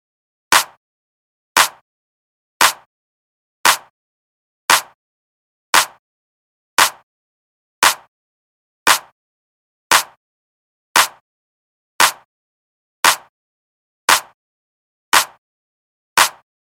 慢吞吞的驴子部落鼓声循环1号
描述：如同标题一样循环包含一个缓慢而简单的部落循环。
标签： 115 bpm Ethnic Loops Drum Loops 2.81 MB wav Key : Unknown
声道立体声